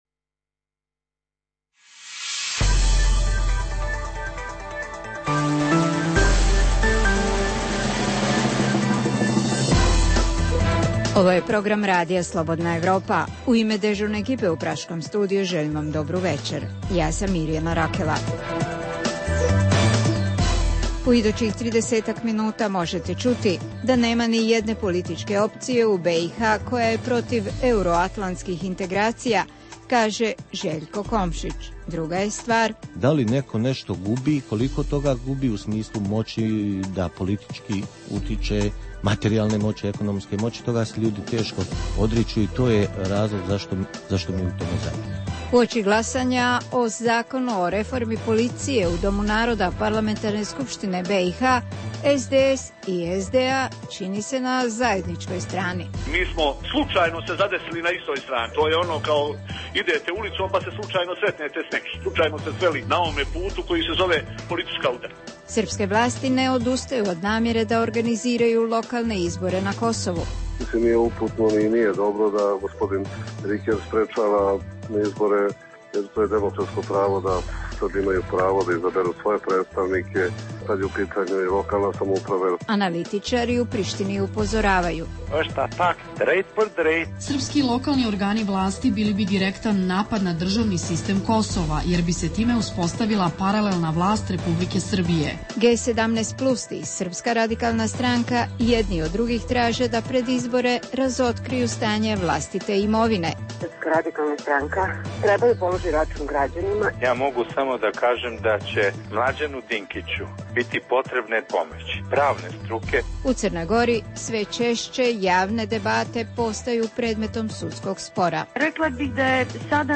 U regionalnom programu možete ćuti - eksluzivni interview sa članom BH Predsjedništva Željkom Komšićem koji je danas posjetio sjedište Radija Slobodna Evropa u Pragu. Analizirat ćemo i reformu policije u BH, te zašto Beograd ustrajava na održavanju lokalnih srpskih izbora na Kosovu, te kako se u Prištini reagira na "želje" Beograda.